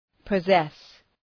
Προφορά
{pə’zes}